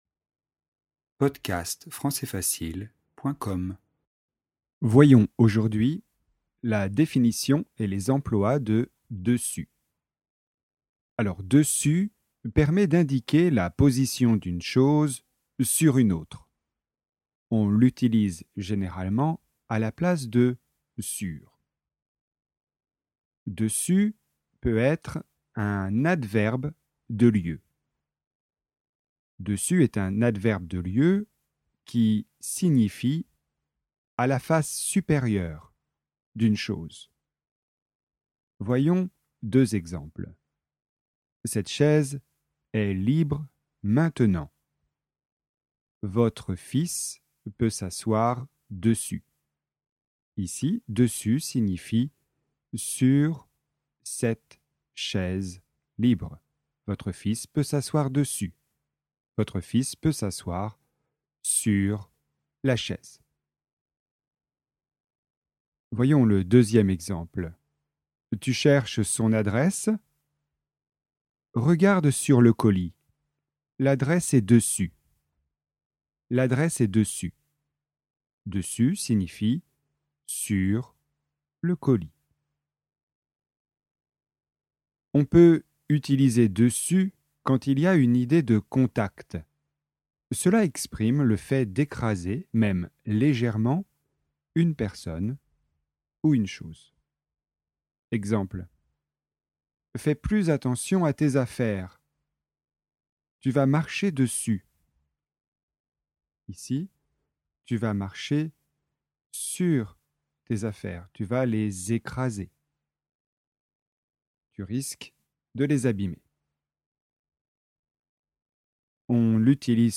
Leçon : 1. Le cas de l'adverbe de lieu 2. Dessus utilisé comme préposition de lieu
prononciation : différence entre dessus et dessous